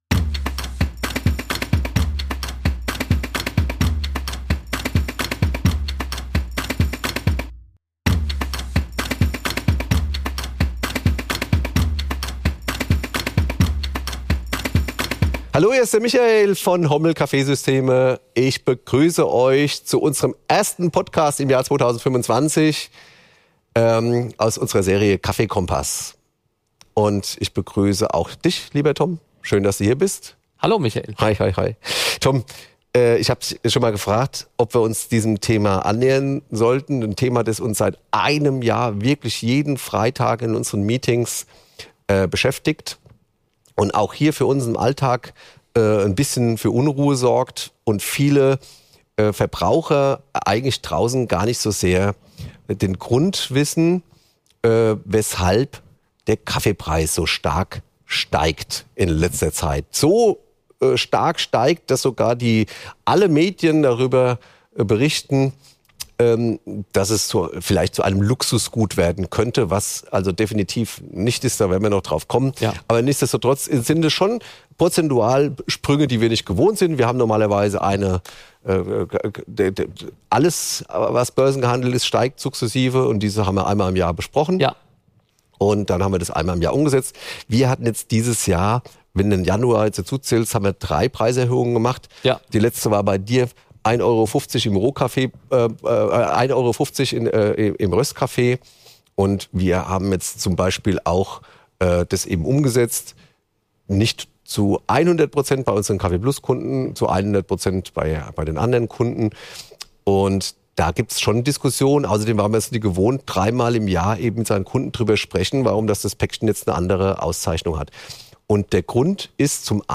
Beides sprüht dem Hörer der KaffeeKOMPASS Podcast-Reihe ab der ersten Sekunde entgegen, wenn zwei ausgewiesene Fachleute rund um das schwarze Gold fachsimpeln. In Folge 69 geht es um ein ernstes Thema, das sowohl die Branche als auch die Konsumenten beschäftigt: Die Rohkaffeepreise steigen rasant.